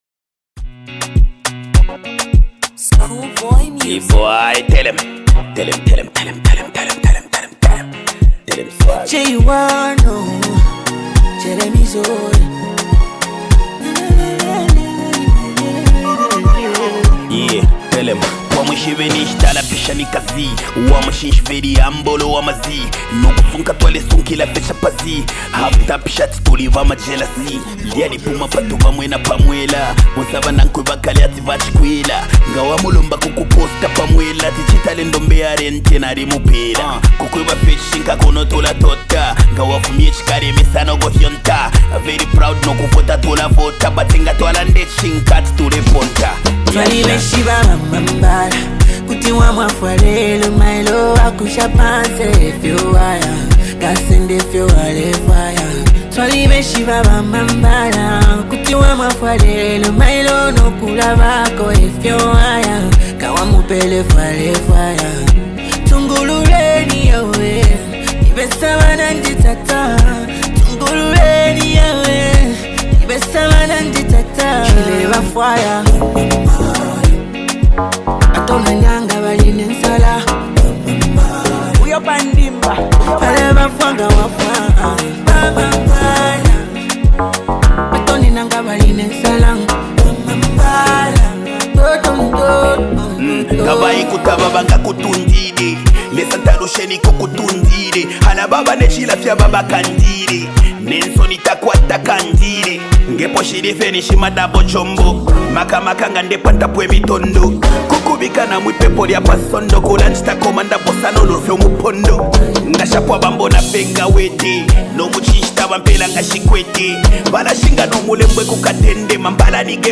Blending Afrobeat and Zambian contemporary rhythms